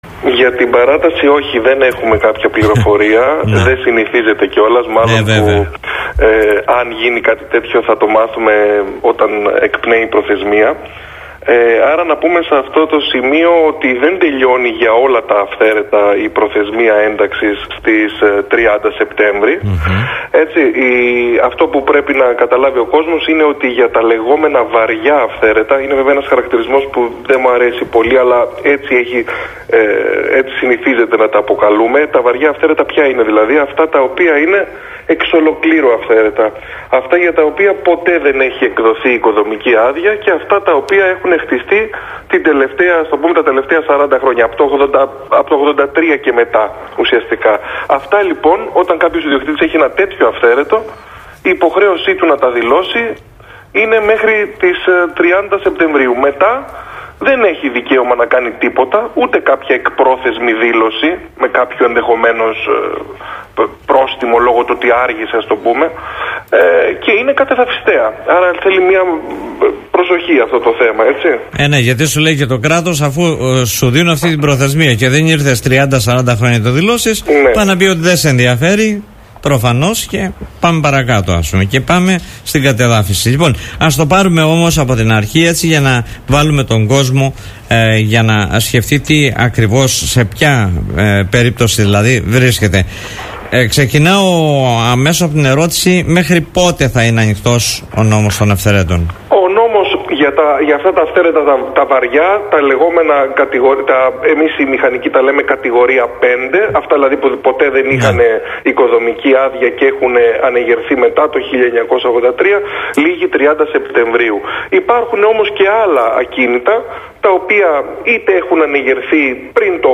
Στην εκπομπή “Δημοσίως” του politica 89.8